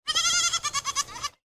دانلود آهنگ بز 2 از افکت صوتی انسان و موجودات زنده
دانلود صدای بز 2 از ساعد نیوز با لینک مستقیم و کیفیت بالا
جلوه های صوتی